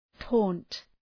Προφορά
{tɔ:nt}